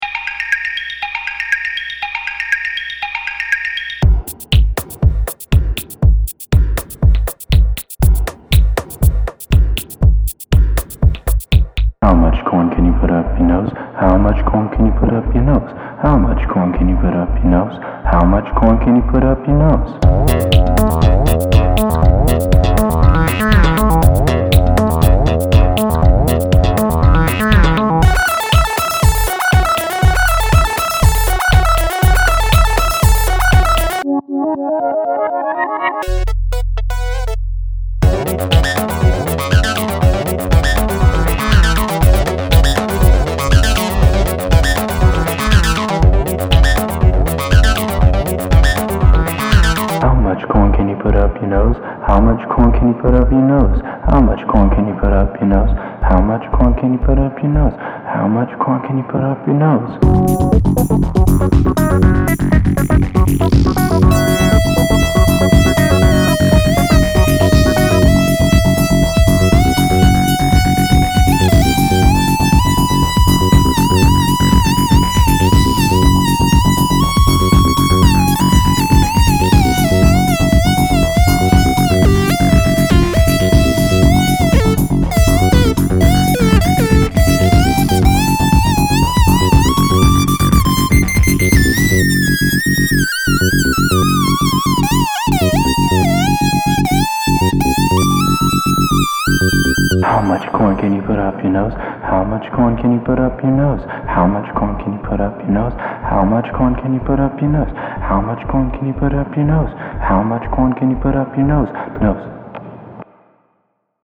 But in the future, (strums guitar, again) WOW!